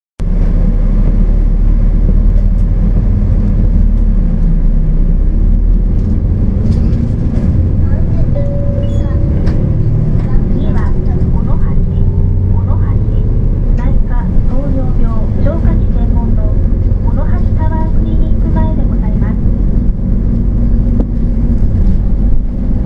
音声合成装置   レゾナント・システムズ(旧ネプチューン)